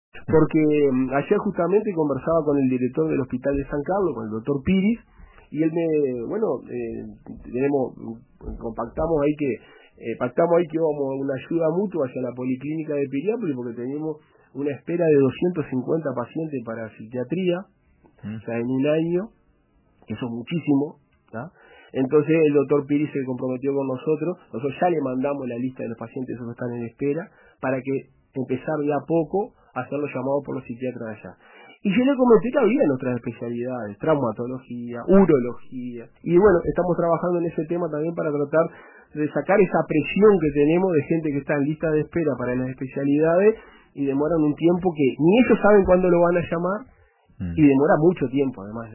participó en el programa “RADIO CON TODOS” de RBC.